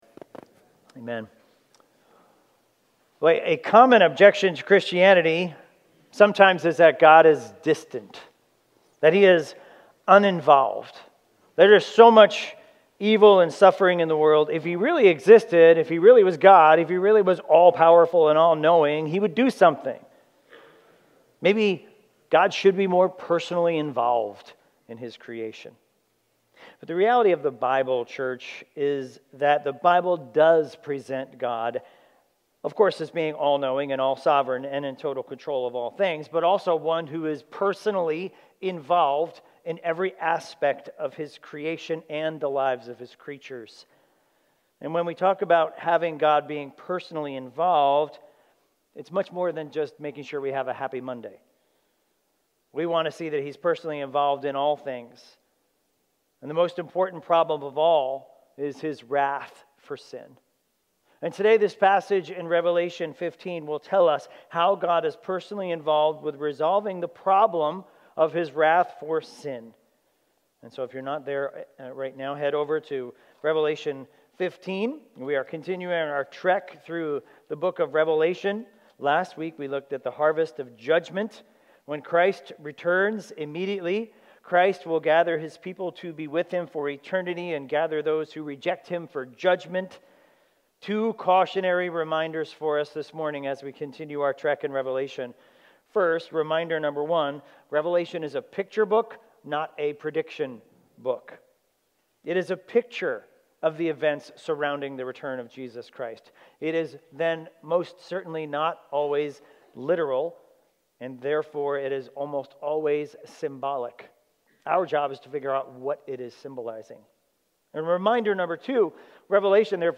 Sermons – Highlands Bible Church
Expositional preaching series through the book of Revelation.